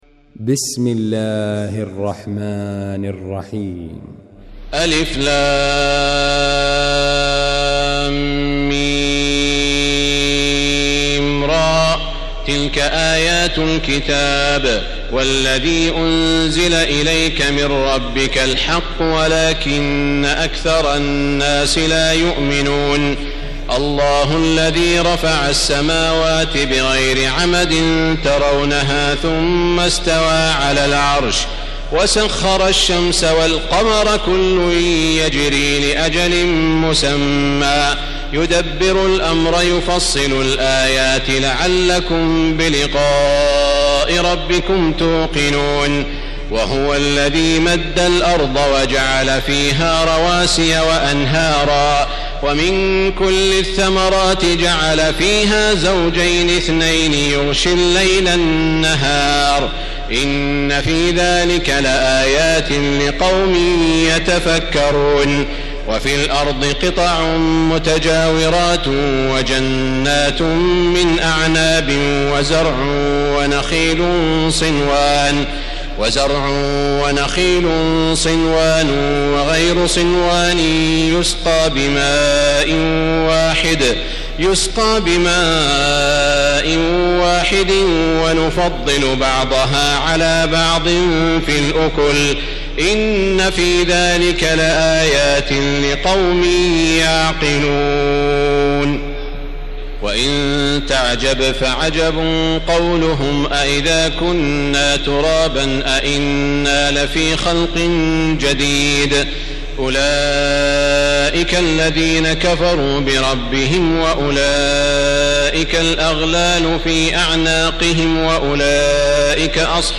المكان: المسجد الحرام الشيخ: سعود الشريم سعود الشريم معالي الشيخ أ.د. عبدالرحمن بن عبدالعزيز السديس الرعد